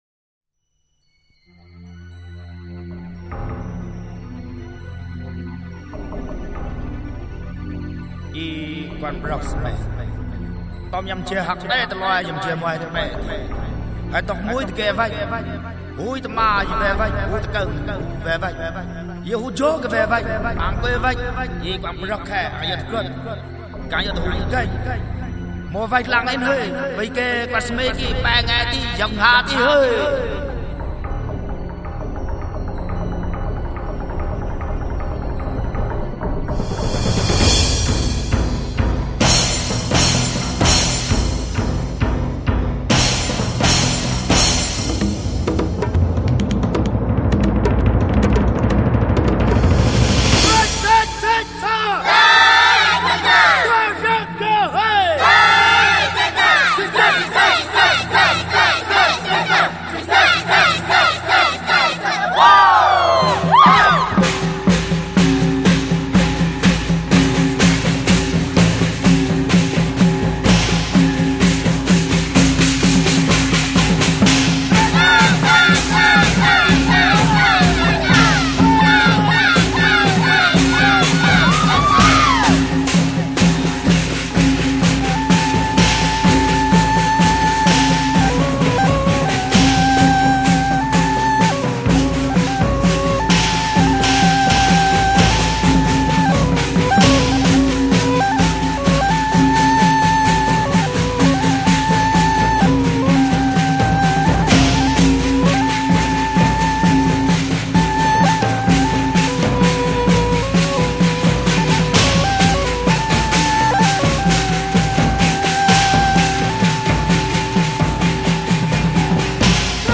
因此總愛在巴烏、竹筒琴奏出的迷人音韻中，跳起婀娜的孔雀舞；
高原上的塔吉克人，以鷹笛吹出族人對鷹的崇敬.....